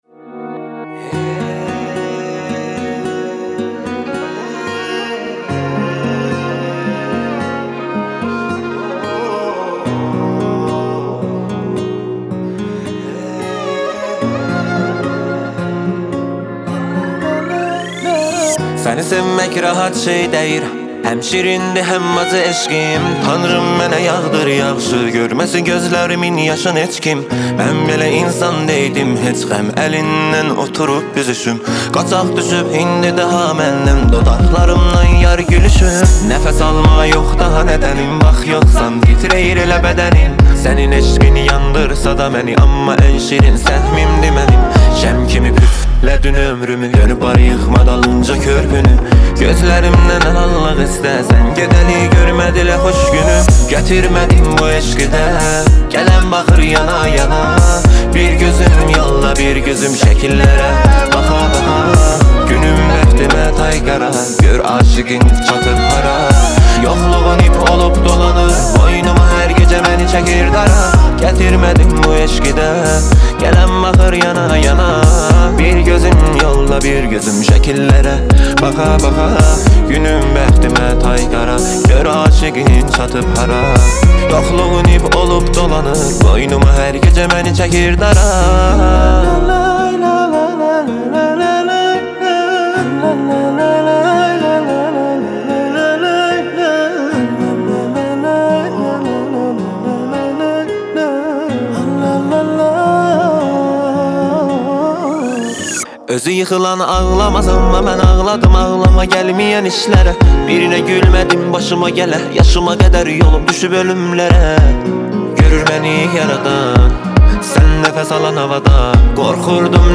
موزیک ترکی